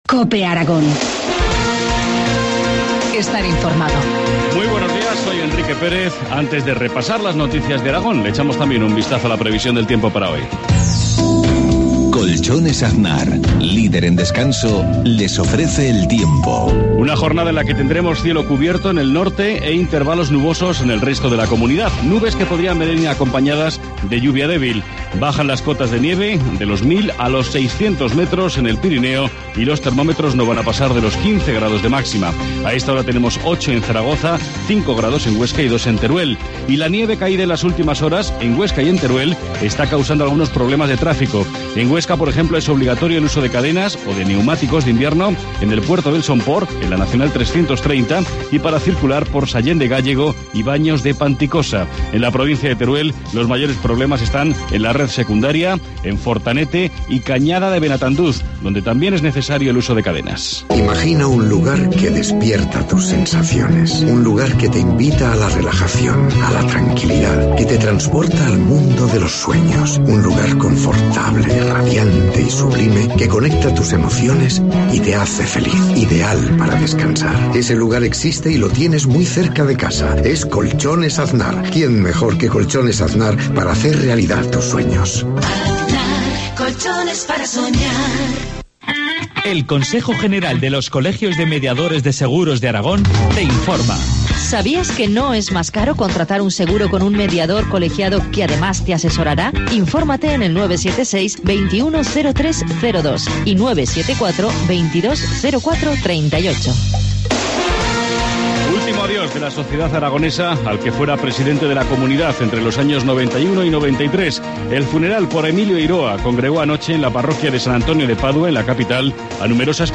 Informativo matinal, martes 12 de marzo, 7.53 horas